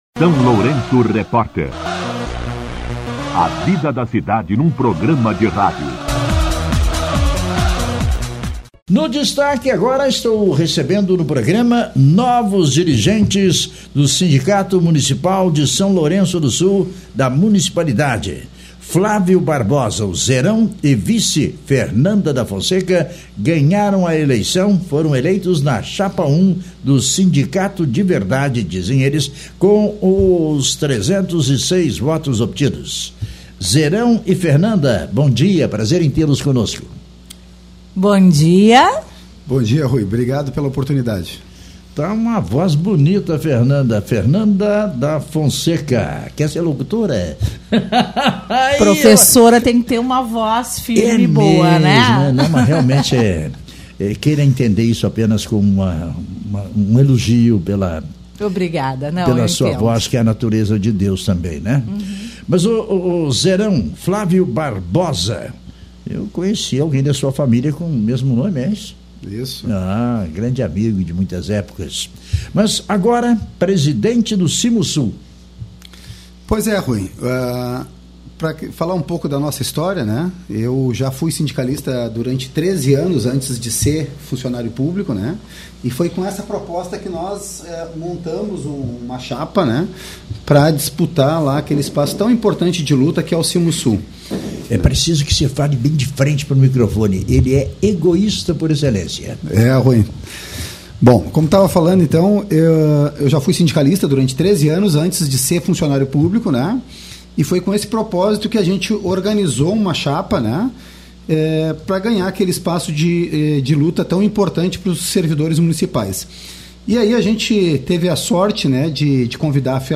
concederam entrevista nesta terça-feira (15), ao SLR RÁDIO